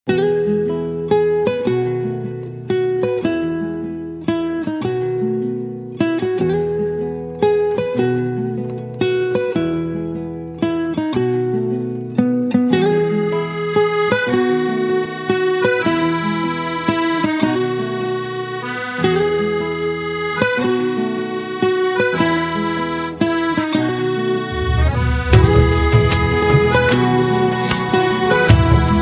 File Type : Mp3 ringtones